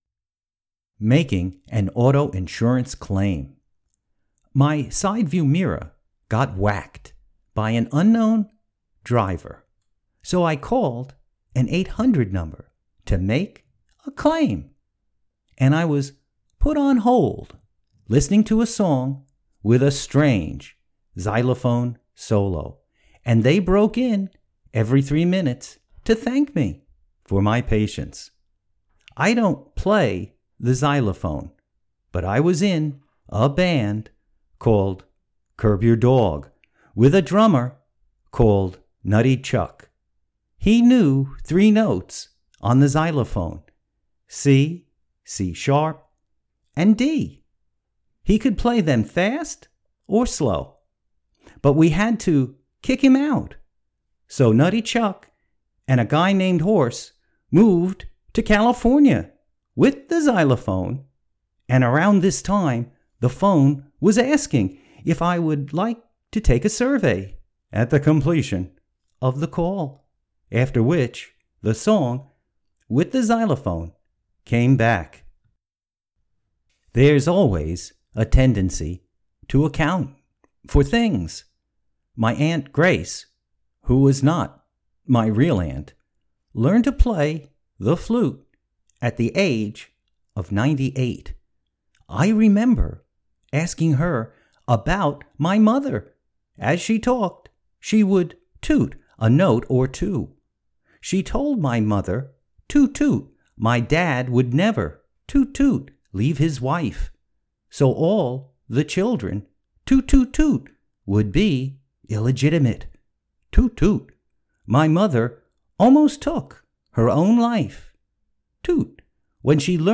Listen to the author read this poem